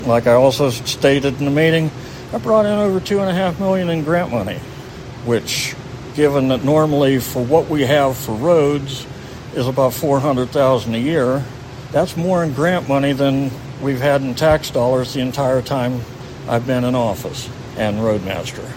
During Tuesday’s public meeting, community members voiced strong opposition to Freeman’s request, with many accusing him of neglecting his duties as road master and not putting the needs of the residents first.
Freeman defended his argument for a higher wage by detailing his background in procuring grant funds.